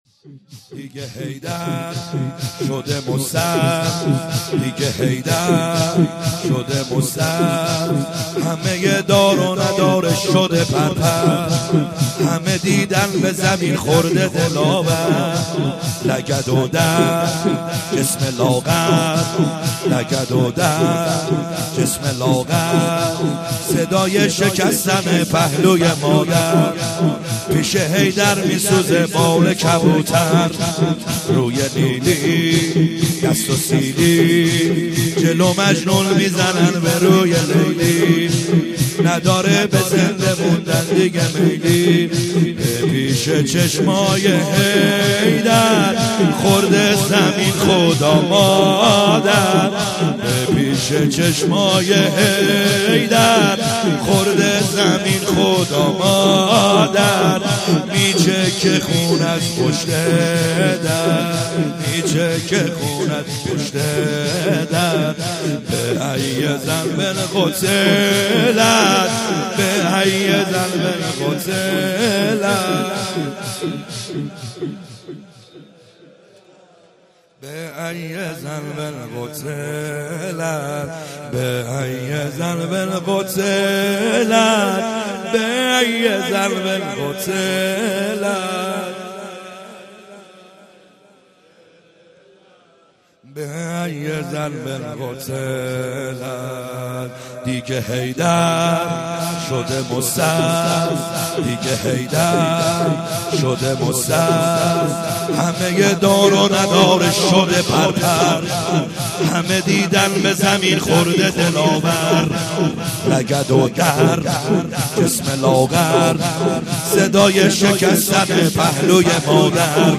خیمه گاه - بیرق معظم محبین حضرت صاحب الزمان(عج) - لطمه زنی | دیگه حیدر شده مضطر